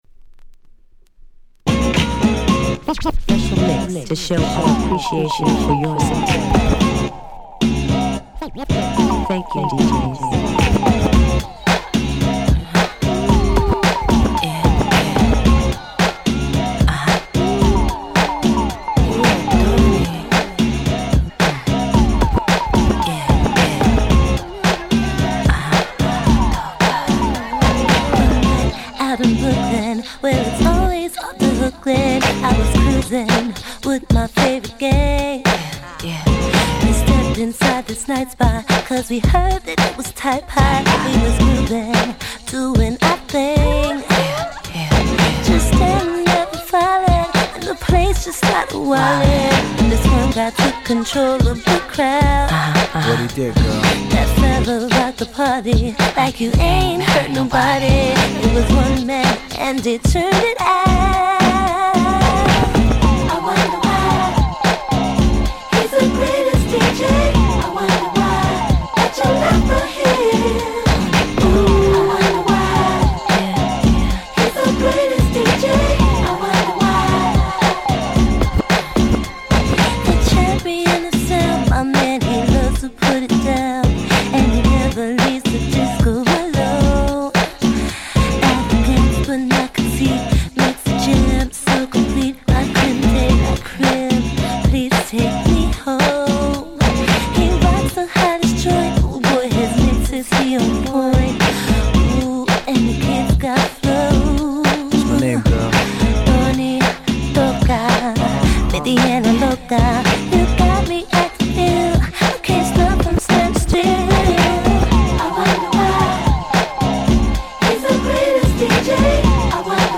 00' Smash Hit R&B / Hip Hop !!